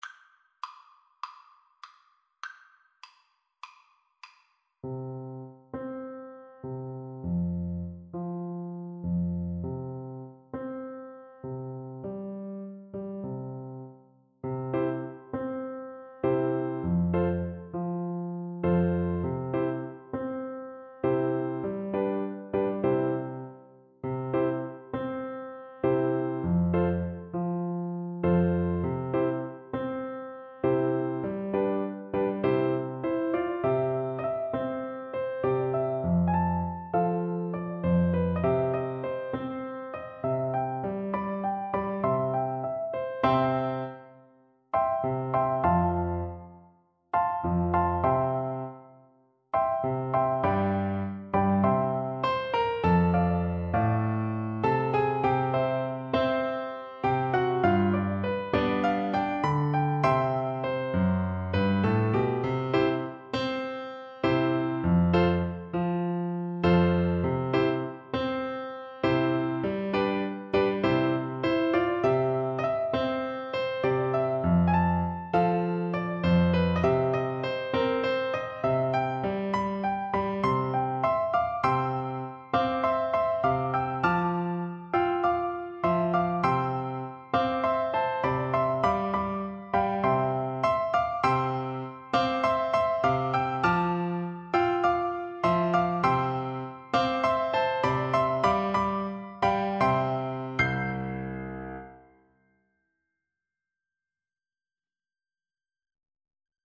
World Africa Liberia Take Time in Life
Flute
Traditional Music of unknown author.
4/4 (View more 4/4 Music)
C major (Sounding Pitch) (View more C major Music for Flute )
Joyfully =c.100